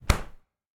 fridge_close.ogg